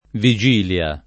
viJ&lLa] s. f. — non vigiglia — nel sign. stretto di «giorno prima della festa», pop. ant. vilia [